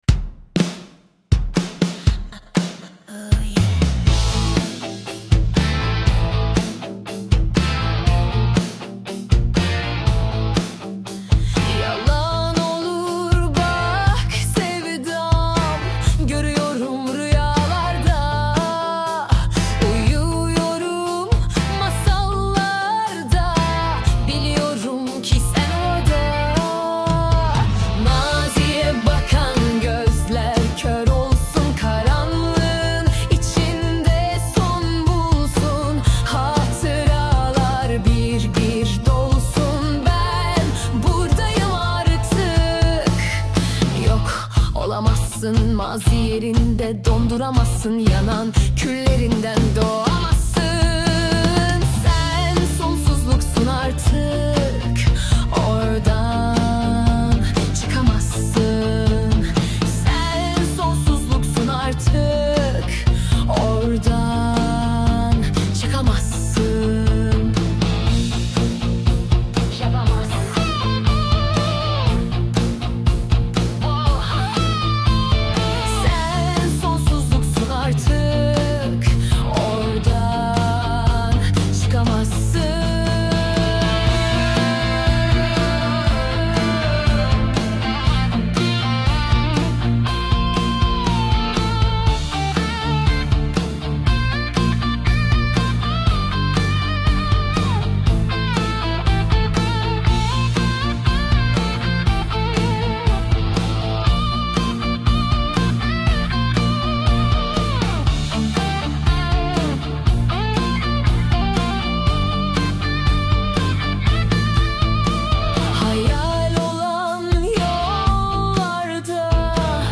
Tür : Pop, Pop EDM, pop rock